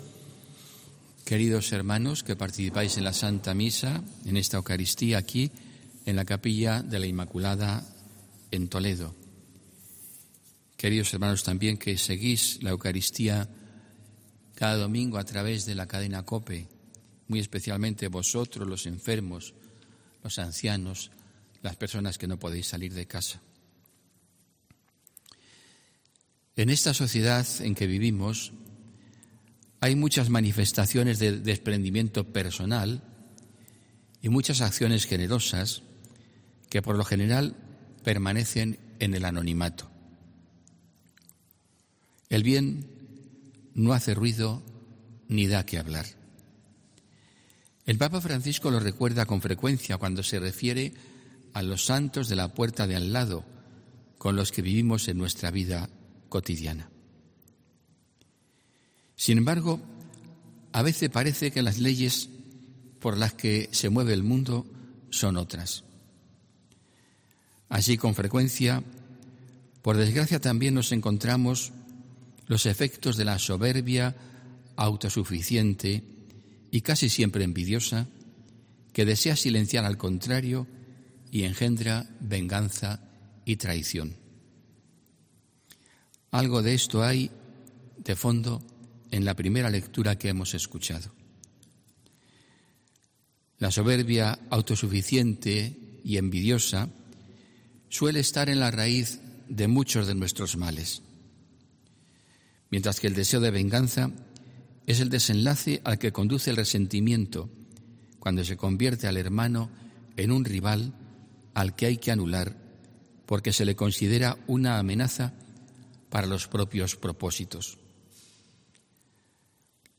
HOMILÍA 21 JUNIO 2020